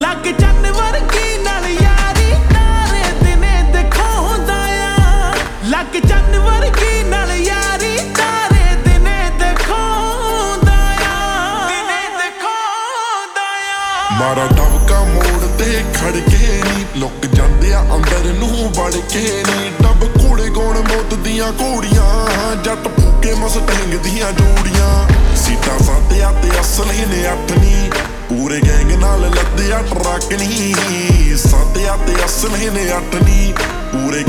Punjabi Songs
Slow Reverb Version
• Simple and Lofi sound
• Crisp and clear sound